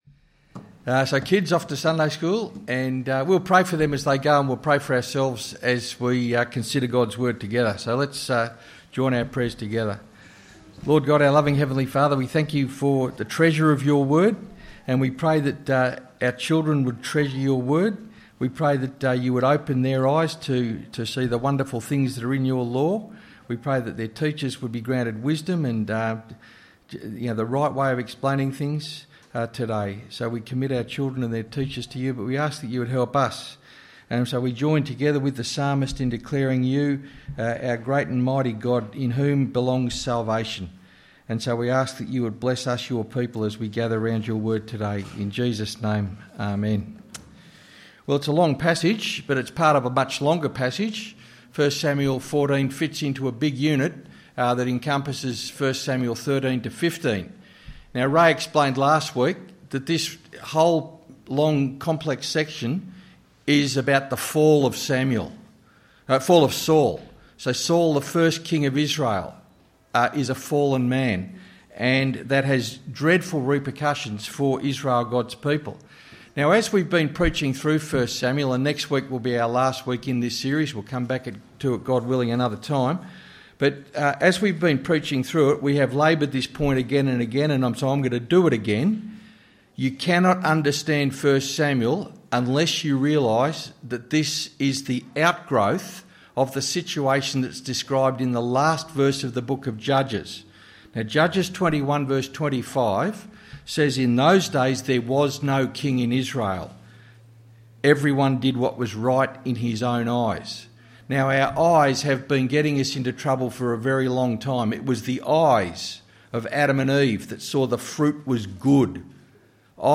Sermon: Leviticus 19:1-37